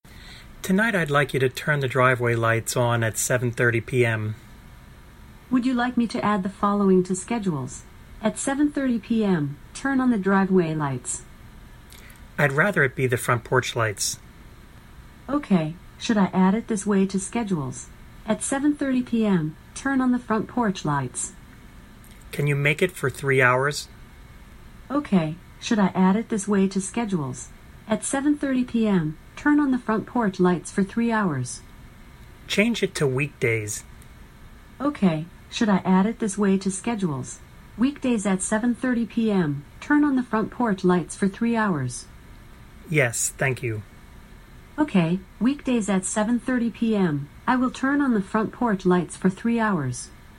Click below to hear our NLP engine handle complex Home Automation commands with AI like no other...